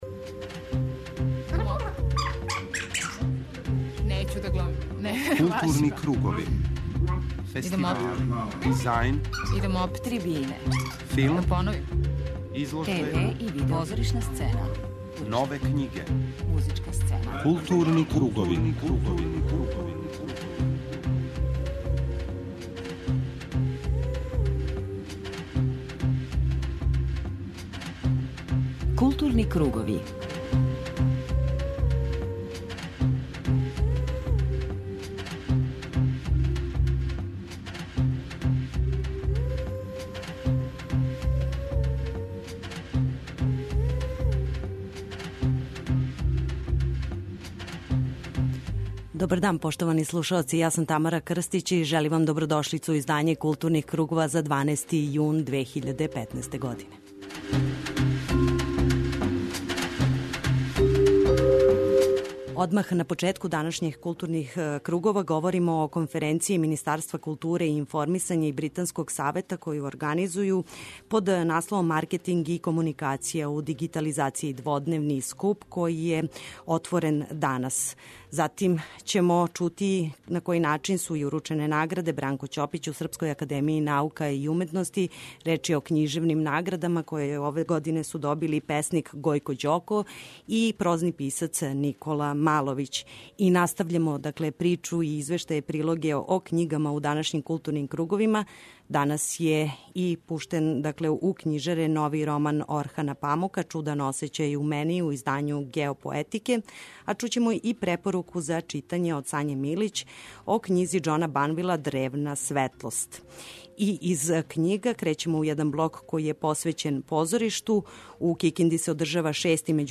У наставку емисије, слушаћете разговор са Давидом Албахаријем о роману "Животињско царство" (Чаробна књига 2014) за који му је ове недеље додељена награда "Исидора Секулић".